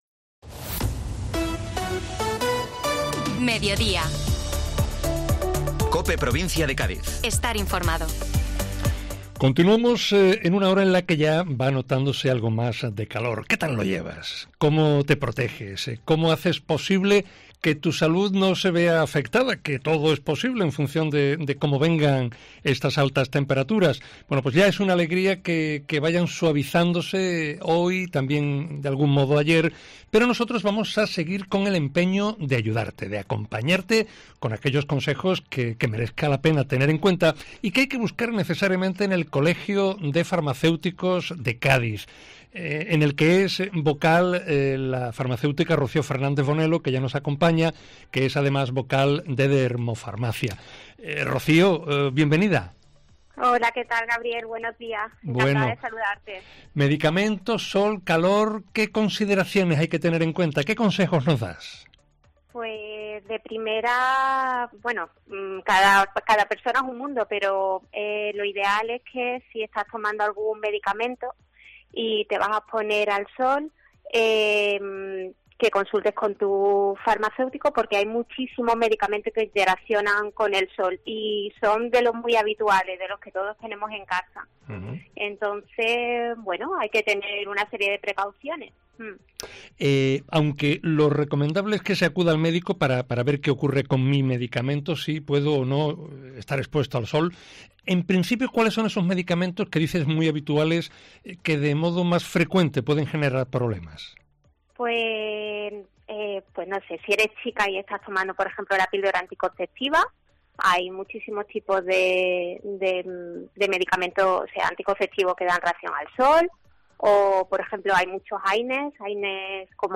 Desde el Colegio Oficial de Farmacéuticos se aconseja en esta entrevista.